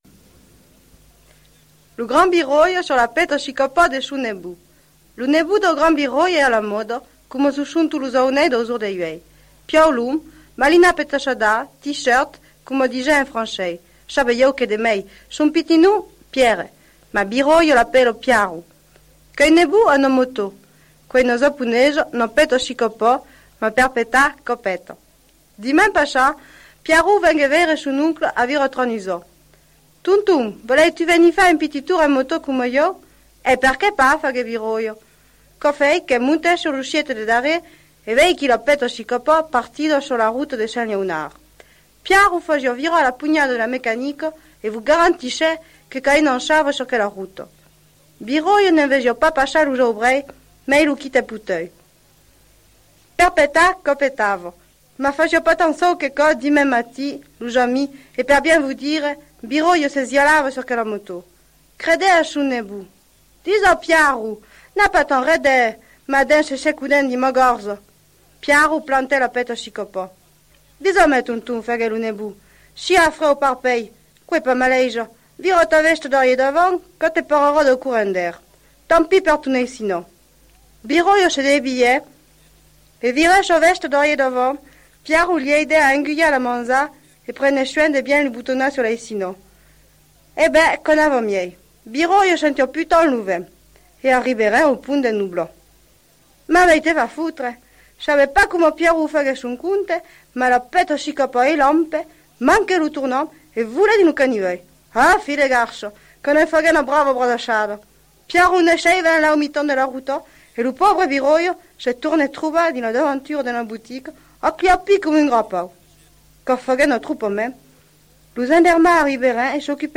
(accent de Pompadour)